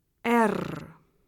En cliquant sur le symbole, vous entendrez le nom de la lettre.
lettre-r.ogg